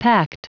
Prononciation du mot pact en anglais (fichier audio)
Prononciation du mot : pact